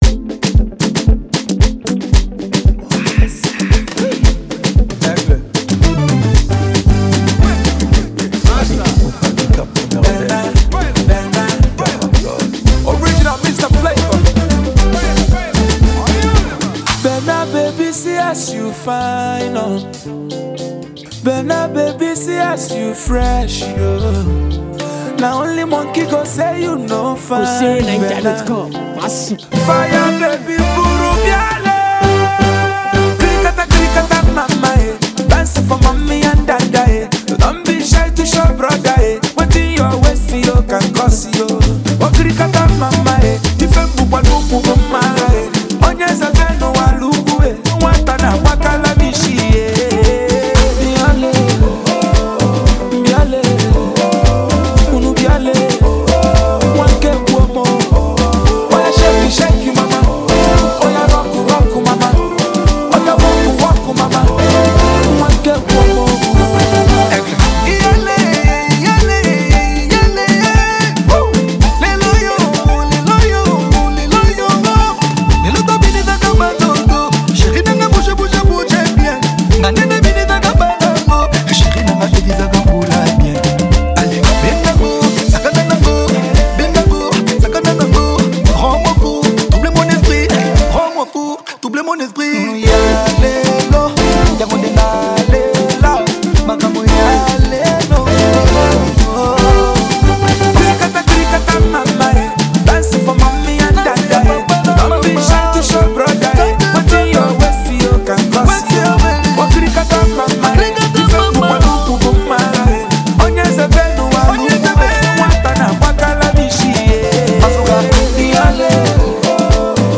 Nigeria’s indigenous highlife music singer